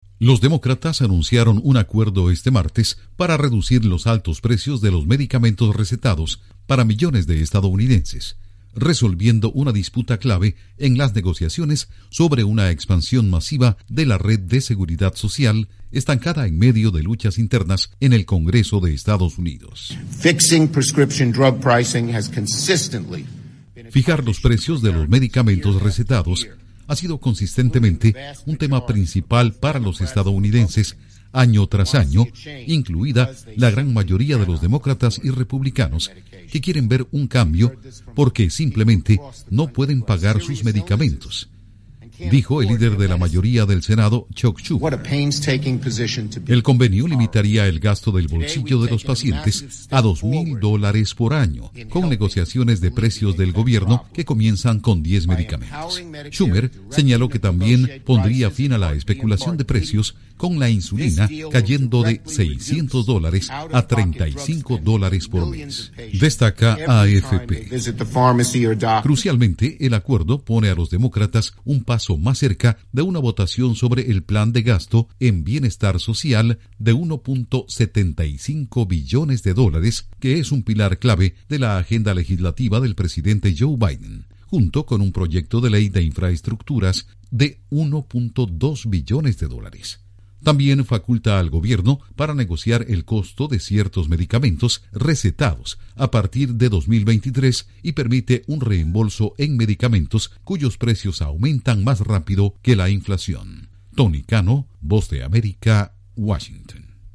Demócratas en disputa anuncian avances en los precios de los medicamentos en Estados Unidos. Informa desde la Voz de América en Washington